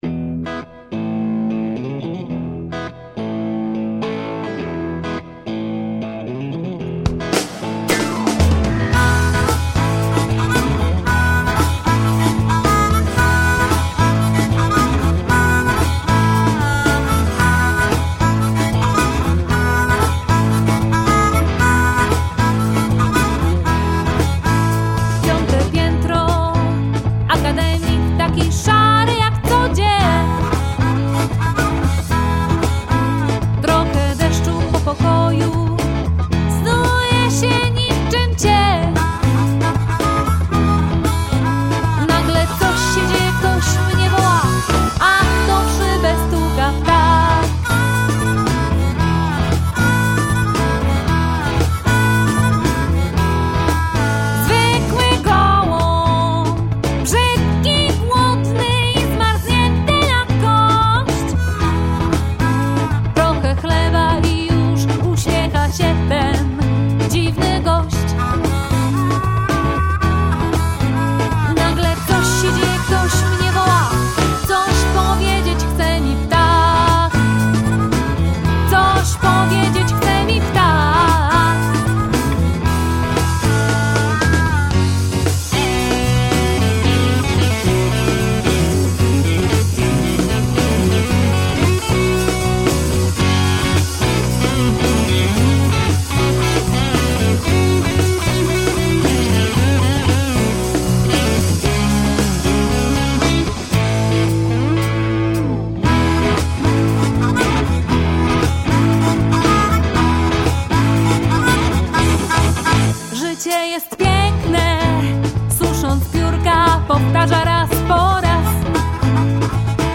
rock blues